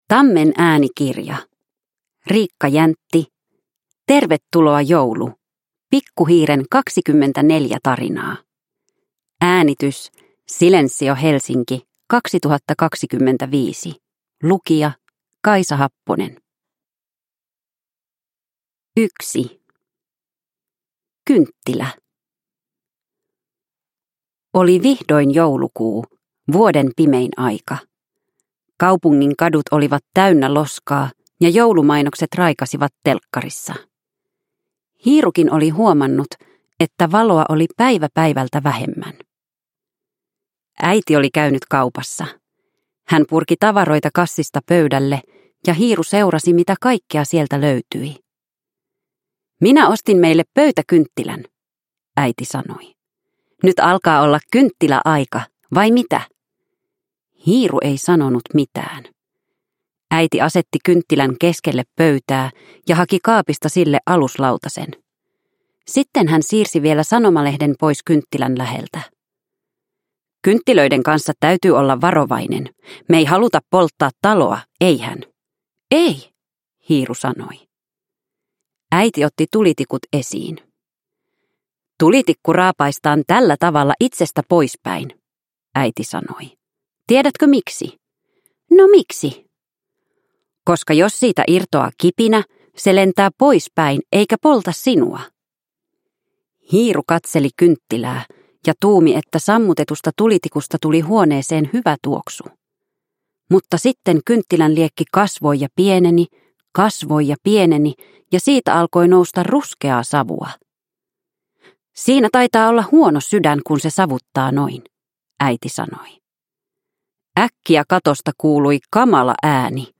Tervetuloa, joulu! Pikku hiiren 24 tarinaa (ljudbok) av Riikka Jäntti